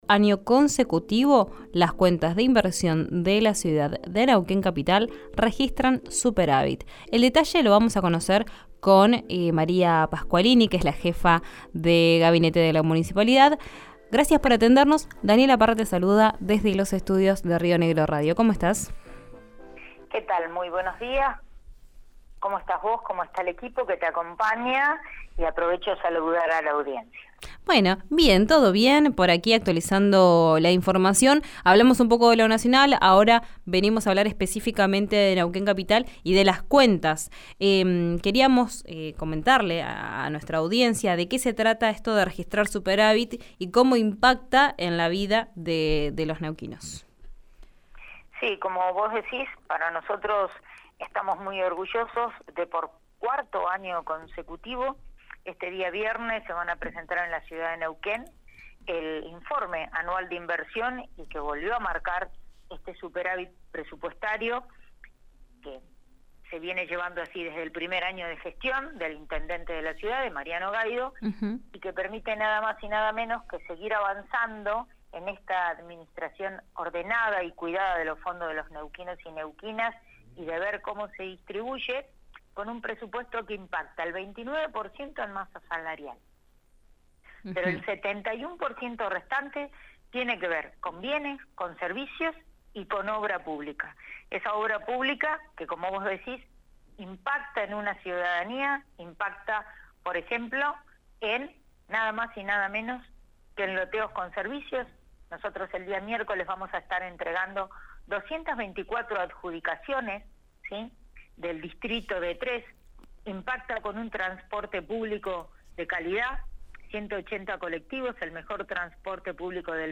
En conversación con RÍO NEGRO RADIO, la jefa de Gabinete de Neuquén, María Pasqualini, indicó que el municipio volvió a registrar superávit presupuestario.
Escuchá a María Pasqualini en RÍO NEGRO RADIO: